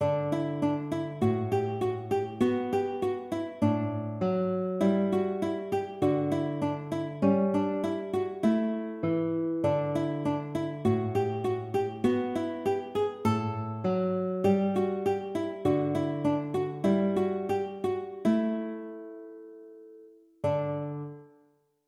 Grade 4 Guitar Sight Reading Exercise
Grade 4 guitar sight reading exercise